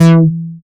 70.05 BASS.wav